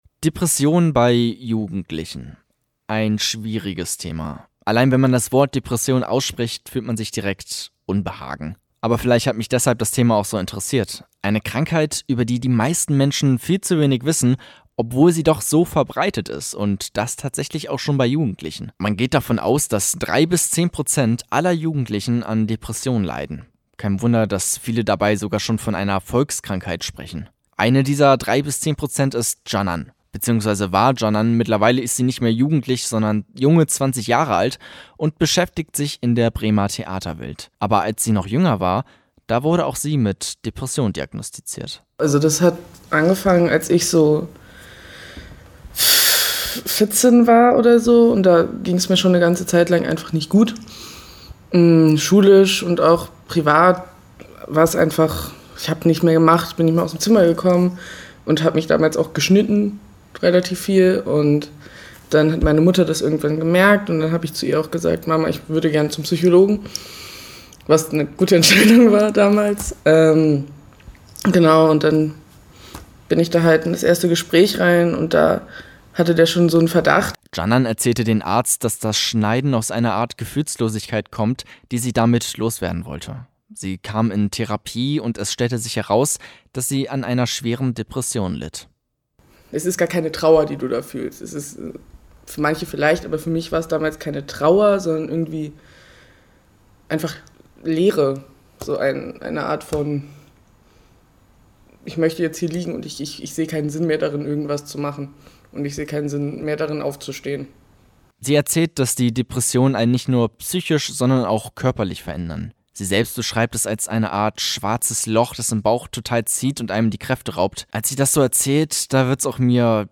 eine Bremer Studentin, erzählt Campus38, wie sie damals eine starke Depression durchstehen musste und was die Schule damit zu tun hatte.